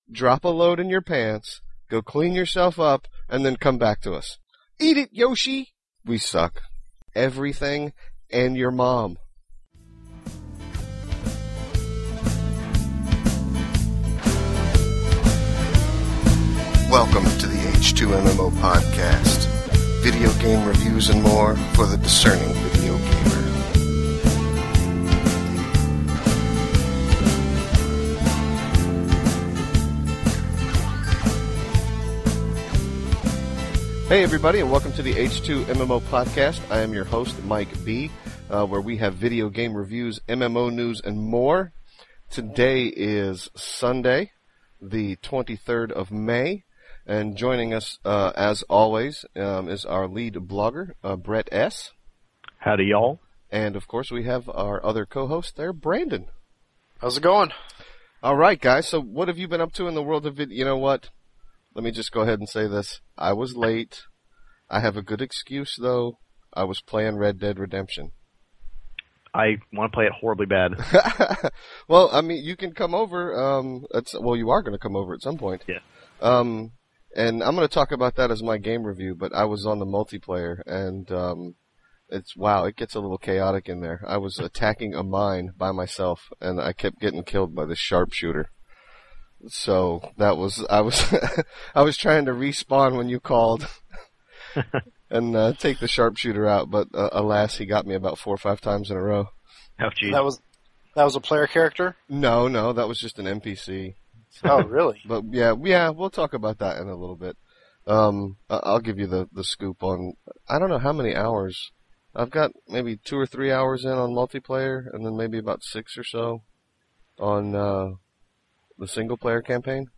In MMO news, we talk about the last episode of Lost, more Monster Hunter Tri (multiplayer), and the latest news on the Star Wars: The Old Republic combat system. And we laugh a lot. I mean to say, a lot.